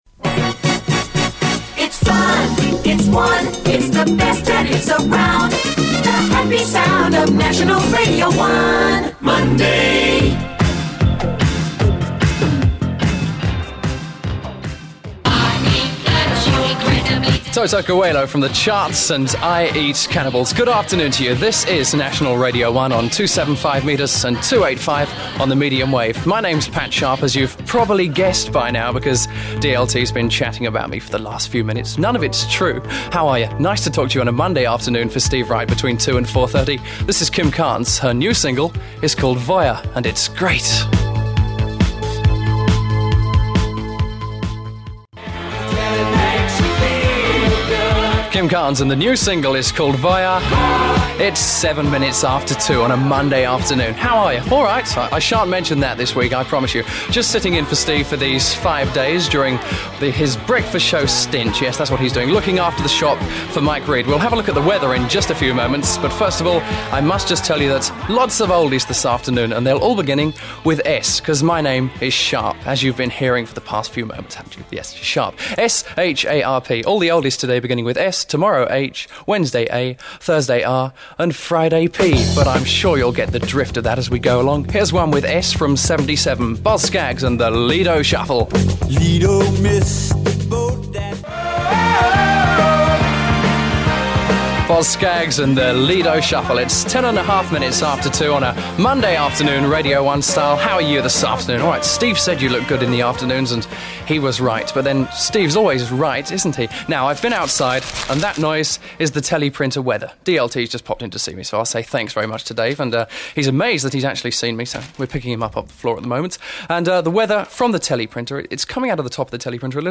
Pat Sharp made his Radio 1 debut in those very circumstances on September 6th 1982. It was, nevertheless, a decent relaxed show, in which he wisely did not try to do Steve’s act.